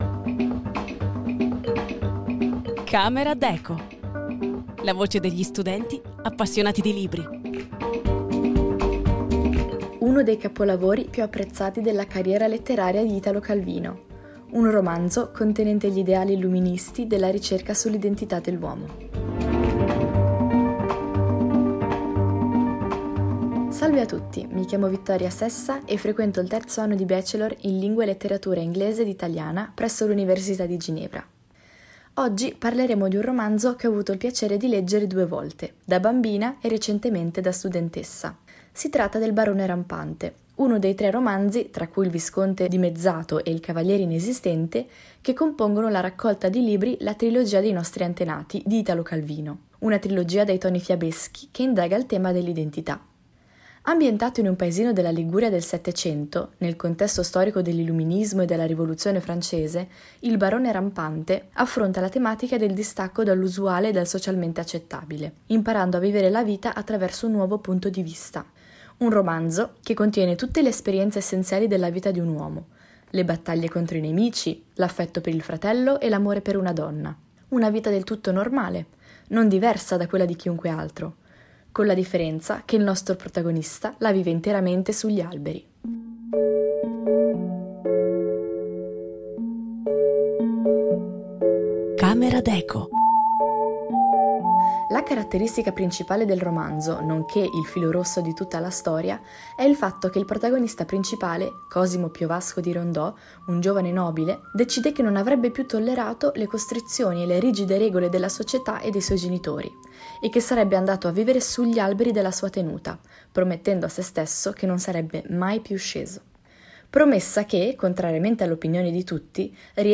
La voce degli studenti appassionati di libri